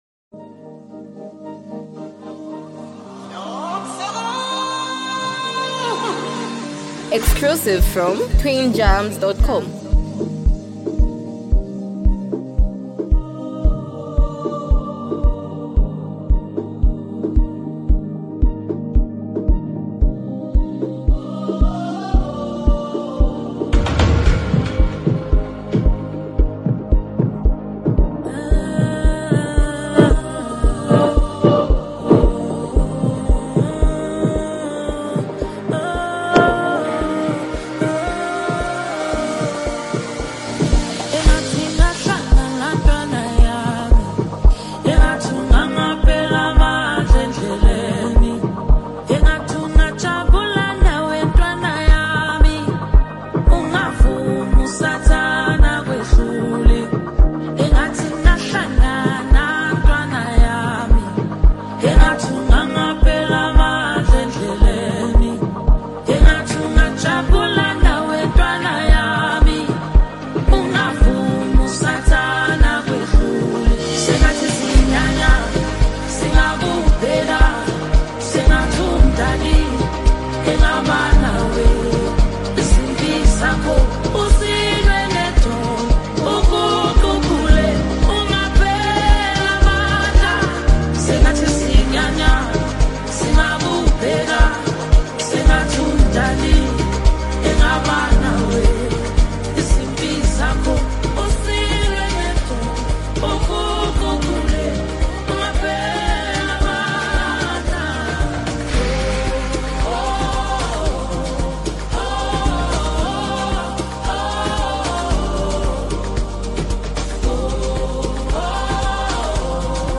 Amapiano love song